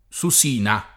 SuS&na o